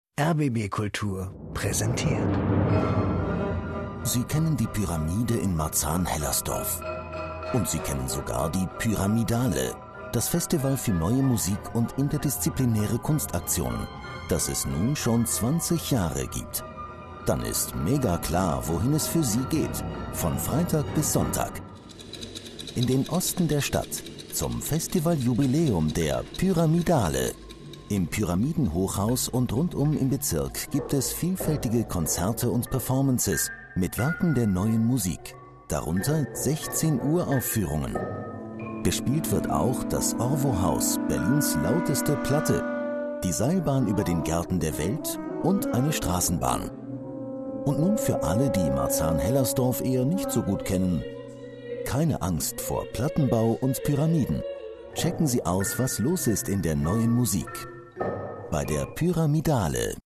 Trailer pyramidale#20 – rbb Kulturradio: